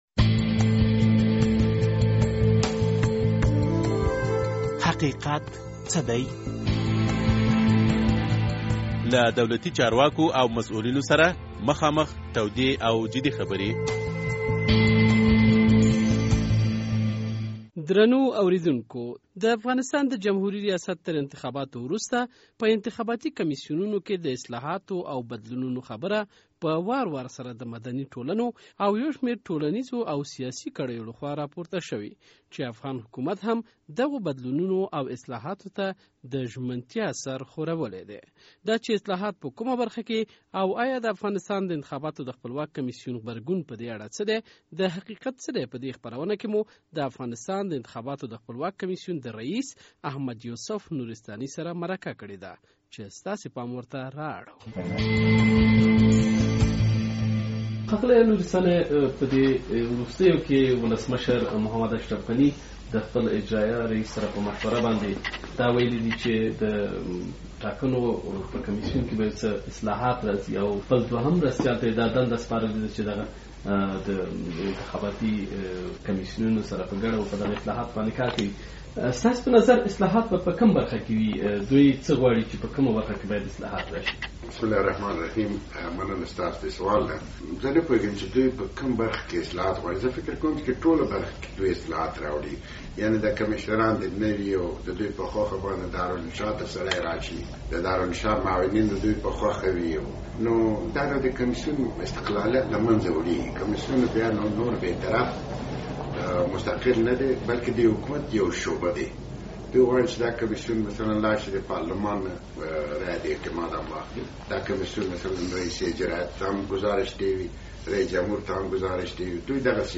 د حقیقت څه دی په دې خپرونه کې مو په انتخاباتي کمیسیونونو کې د اصلاحاتو په اړه د افغانستان د انتخاباتو د خپلواک کمیسیون له ریس احمد یوسف نورستاني سره مرکه کړې ده.